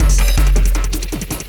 53 LOOP 01-R.wav